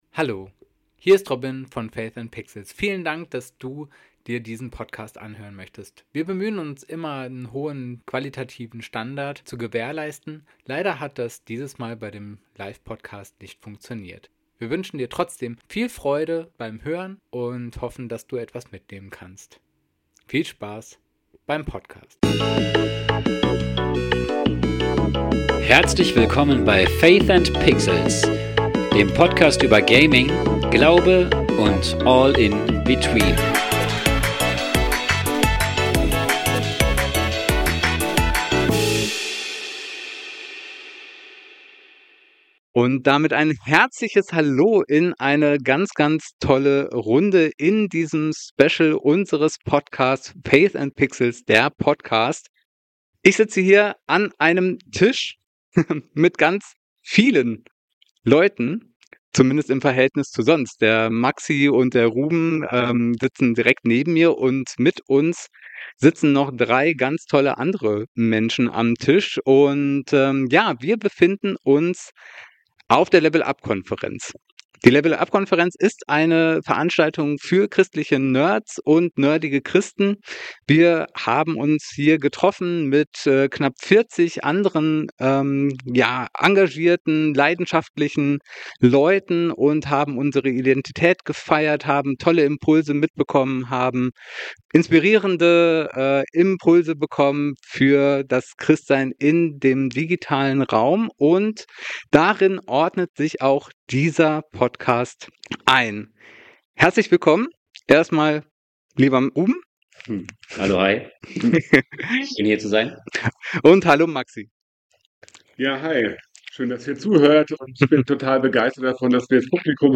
Beschreibung vor 4 Monaten Vom 21. bis 22. November 2025 fand die Level Up-Konferenz im mittelhessischen Ewersbach statt.
Anmerkung: Bitte verzeiht die niedrige Tonqualität. Aufgrund des neuartigen Settings ist uns ein Fehler passiert, der darin resultierte.